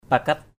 /pa-kat/ (d.) tầng, lầu = étage. ngap sang klau pakat ZP s/ k*~@ pkT xây lầu ba tầng.